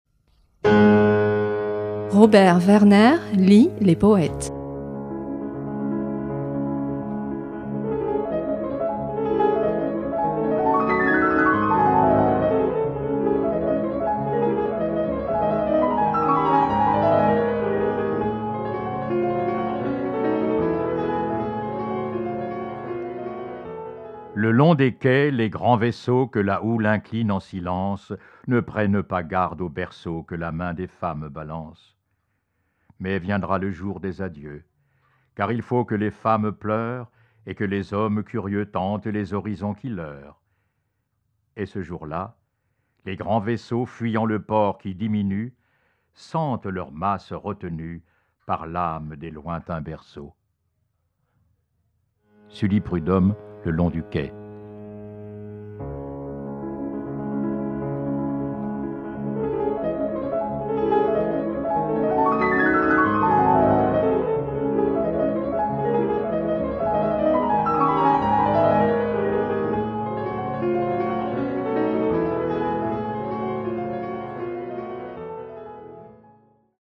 lit cette semaine Le long du quai, de René-François Sully Prudhomme (1839-1907), élu membre de l’Académie française en 1881.